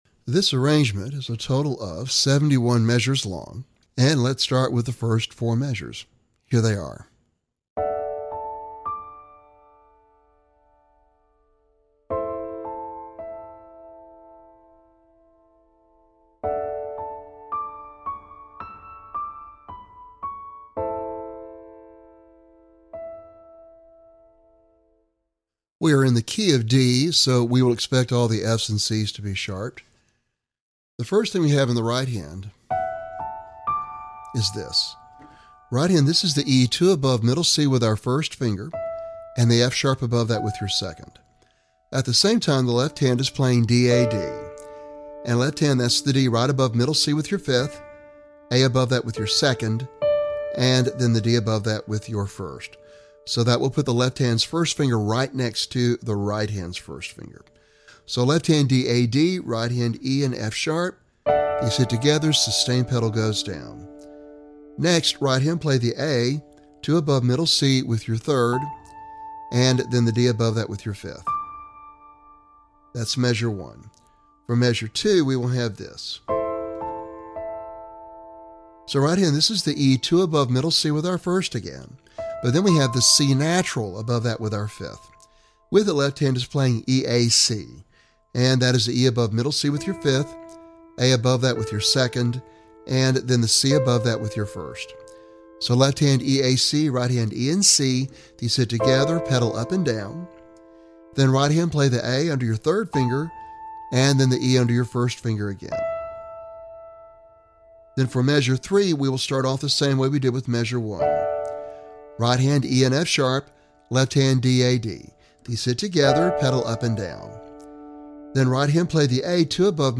Piano Solo - Intermediate